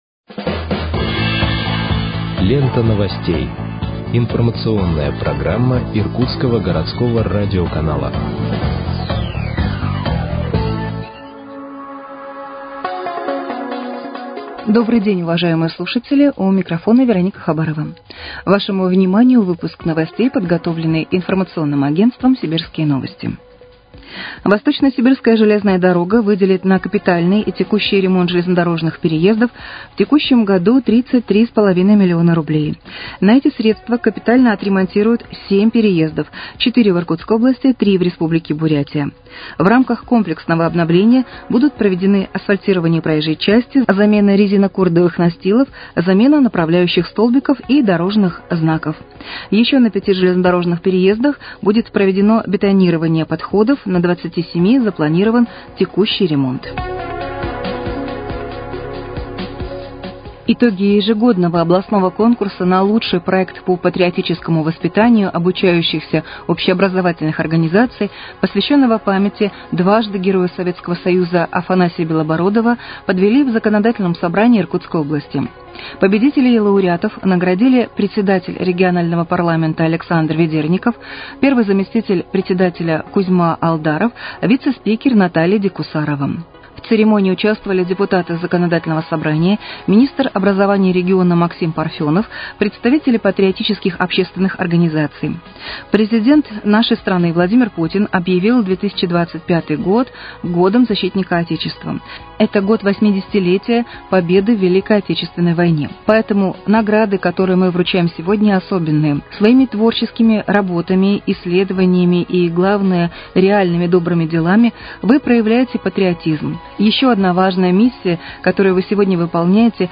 Выпуск новостей в подкастах газеты «Иркутск» от 3.03.2025 № 2